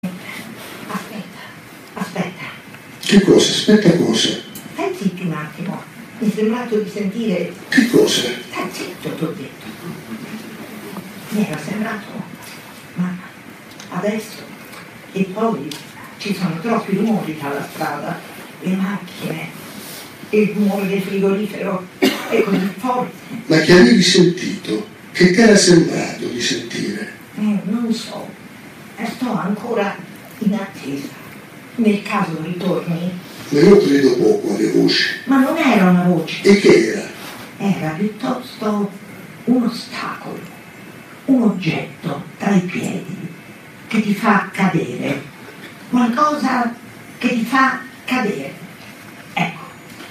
Nanni Moretti e Piera Degli Esposti leggono testi da Cieli celesti